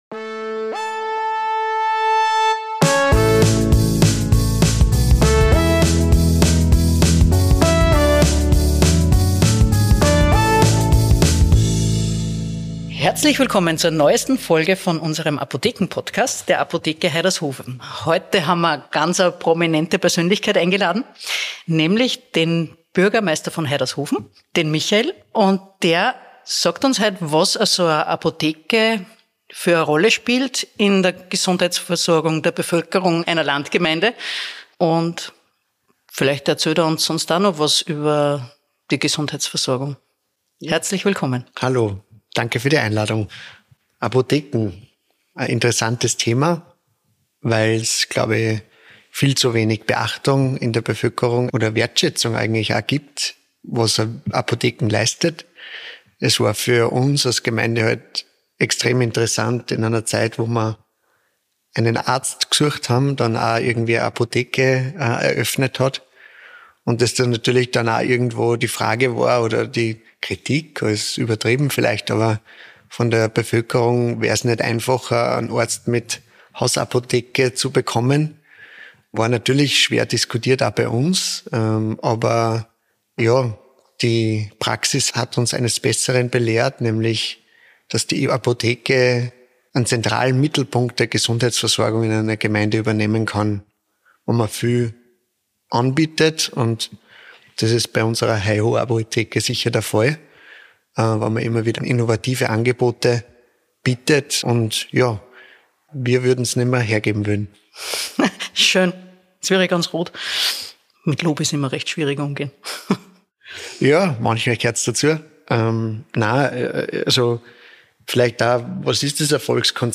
In dieser Folge des Apodcasts der HAIHO Apotheke ist Bürgermeister von Haidershofen Michael Strasser zu Gast.